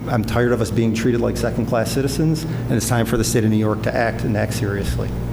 City Councilor Jimmy Giannettino said New York needs to act: